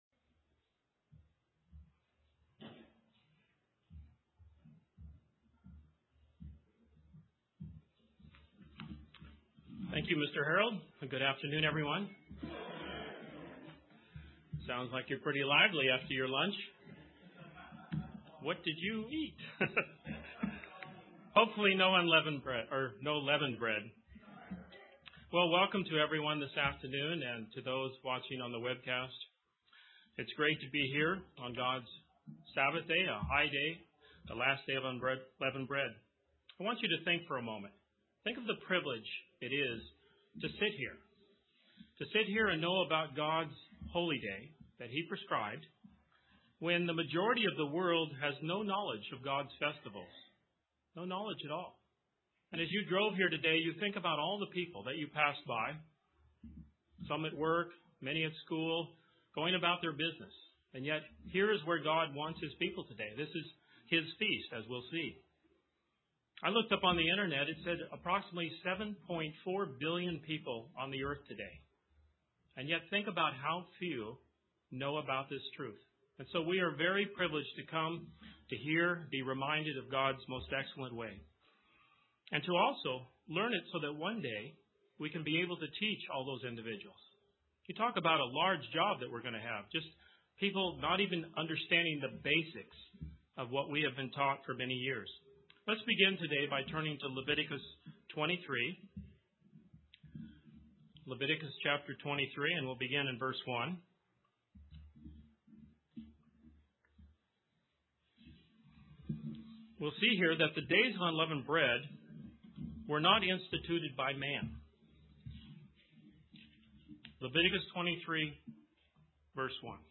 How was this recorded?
What does God want us to learn by these days He's given us to rehearse His plan? How are we being made ready to teach all those who will come to know His truth? This message was given on the Last Day of Unleavened Bread.